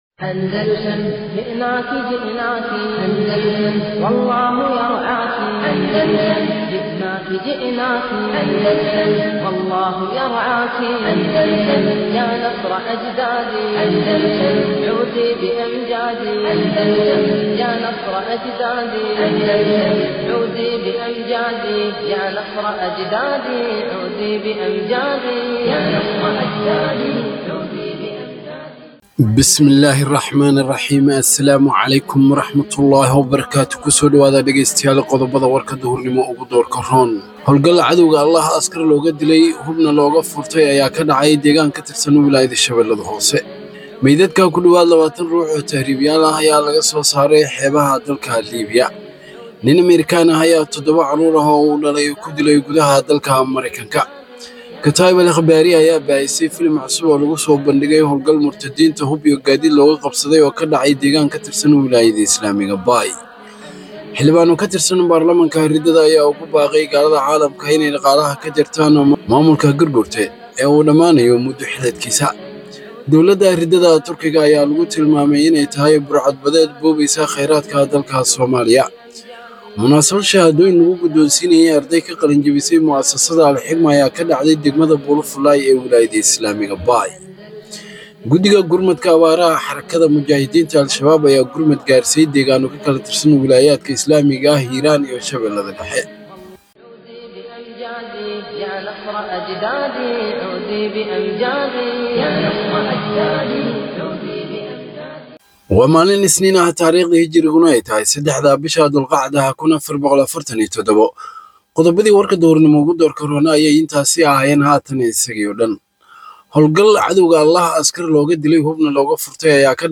Warka Duhurnimo waa mid kamid ah wararka ugu muhiimsan ee ka baxa Idaacadda Al-andalus.